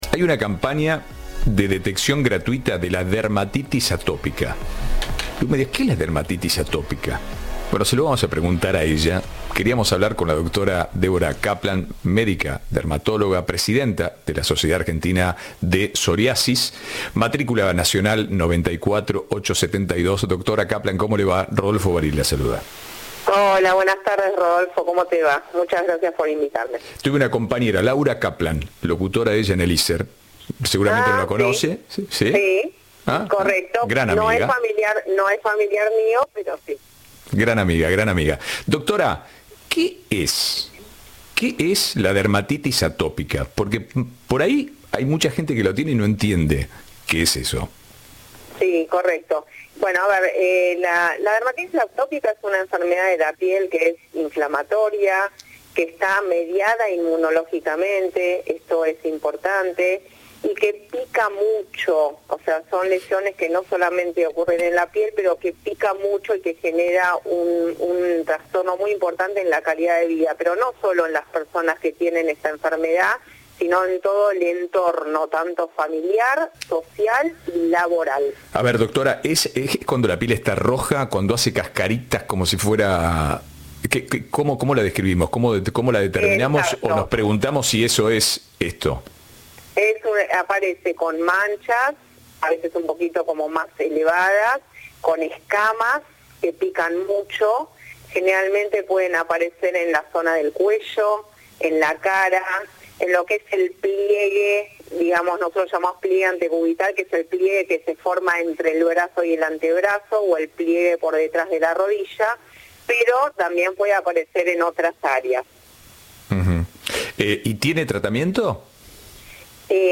Entrevista de Ahora País